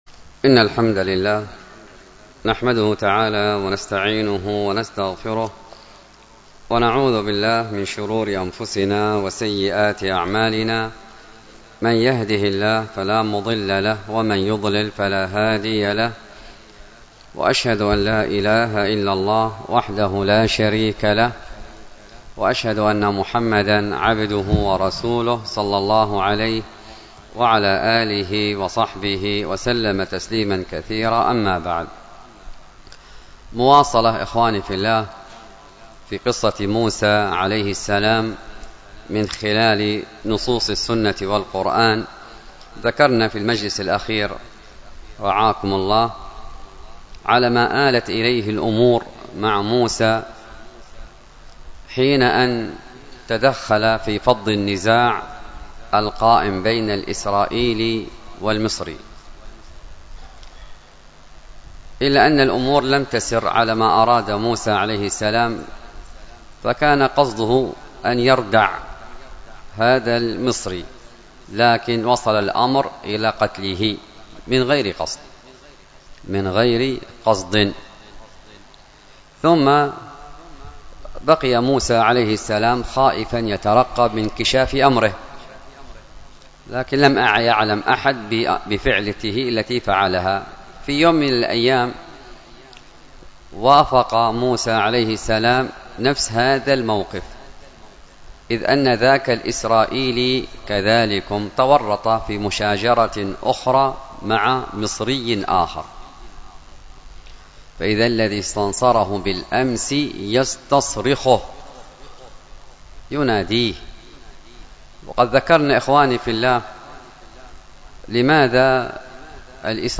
الدرس في الصحيح المسند مما ليس في الصحيحين 137، ألقاها